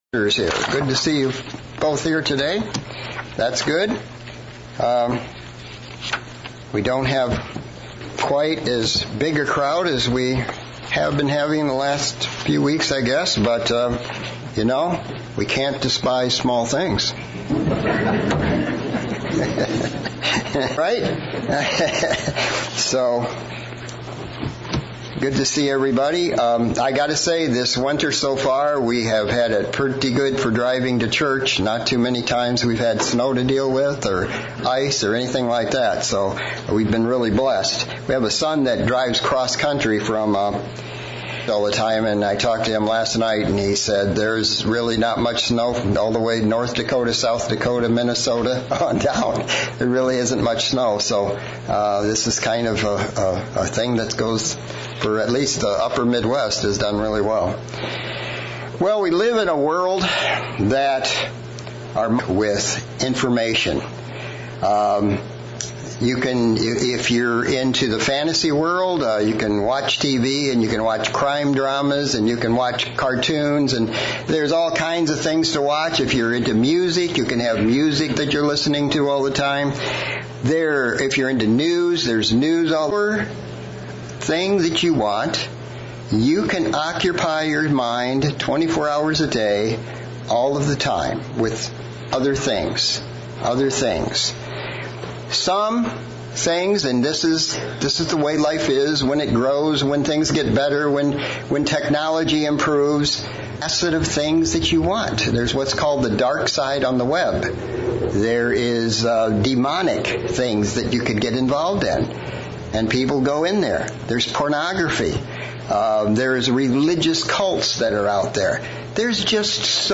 Sermon looking at the importance of meditation, what is meditation, and why it is such a valuble tool in our Christian toolbox.